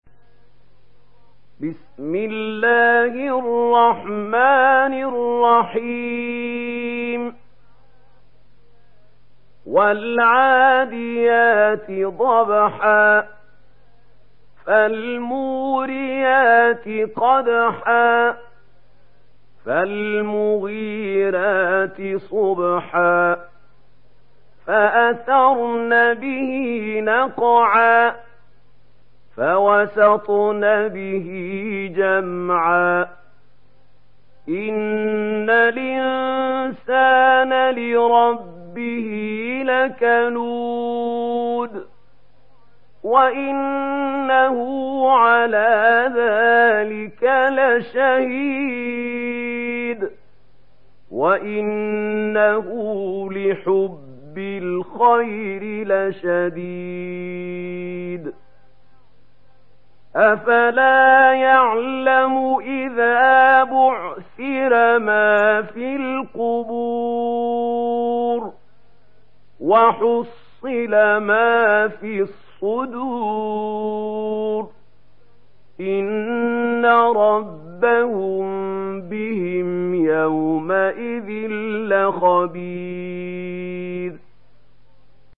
Surah Al Adiyat Download mp3 Mahmoud Khalil Al Hussary Riwayat Warsh from Nafi, Download Quran and listen mp3 full direct links